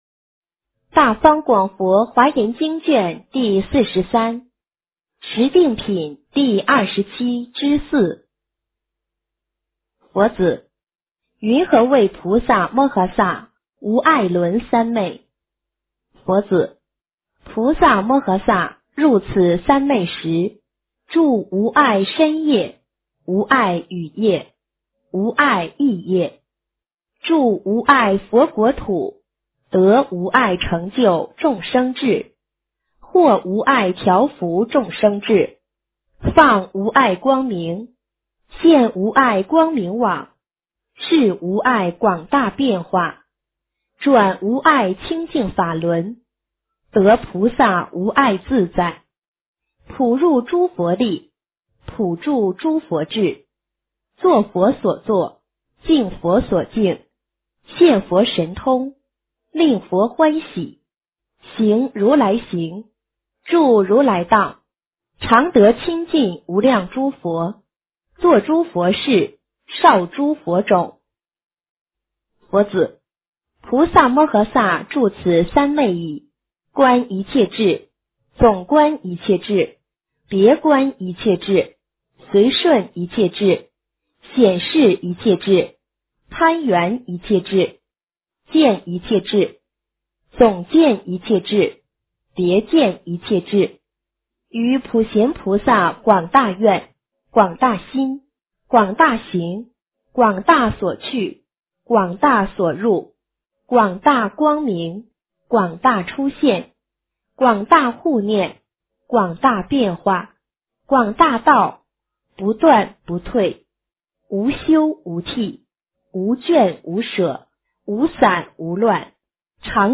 华严经43 - 诵经 - 云佛论坛